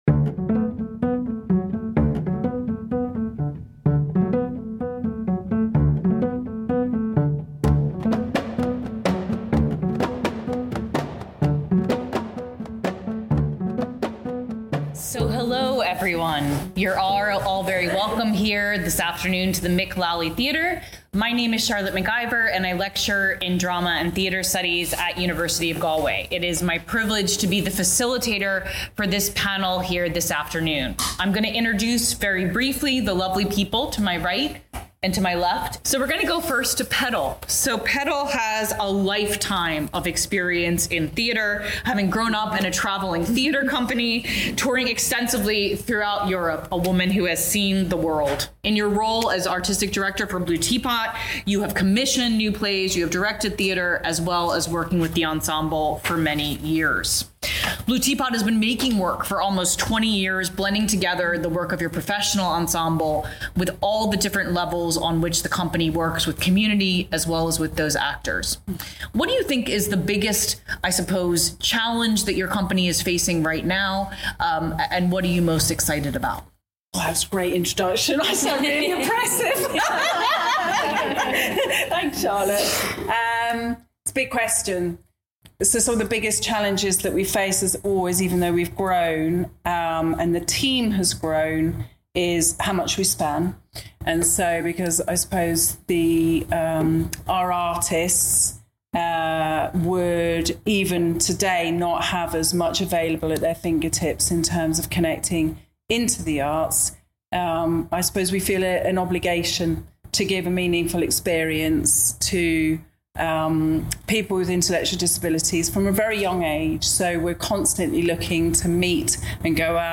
While we are seeing positive steps towards the development of more inclusive arts practice, there are still many challenges and obstacles in the way of truly inclusive development, presentation and touring practices. This panel of creatives discuss this practice, sharing the continuing challenges as well as learnings and small wins along the way.